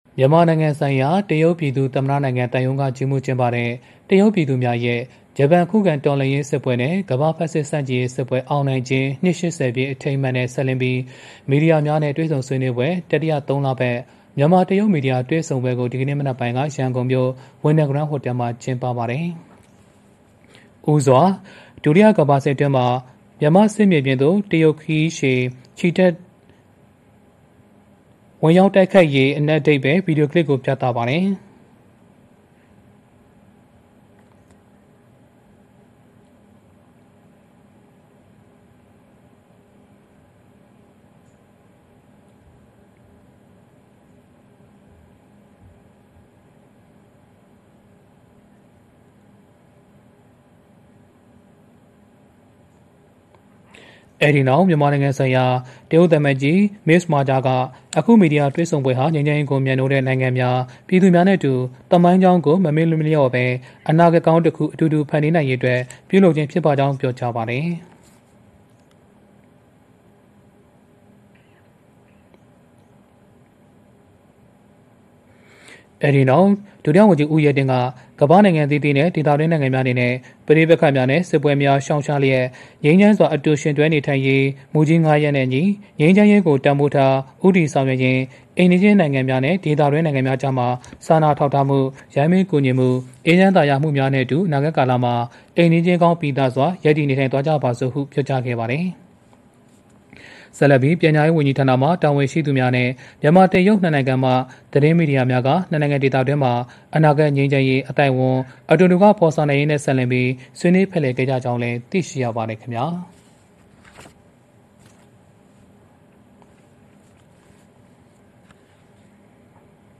ပြန်ကြားရေးဝန်ကြီးဌာန၊ ဒုတိယဝန်ကြီးဦးရဲတင့် တရုတ်ပြည်သူများ၏ ဂျပန်ခုခံတော်လှန်ရေးစစ်ပွဲနှင့် ကမ္ဘာ့ဖက်ဆစ်ဆန့်ကျင်ရေး စစ်ပွဲအောင်နိုင်ခြင်း နှစ် (၈၀) ပြည့် အထိမ်းအမှတ်နှင့်စပ်လျဉ်း၍ မီဒီယာများ တွေ့ဆုံဆွေးနွေးပွဲသို့ တက်ရောက်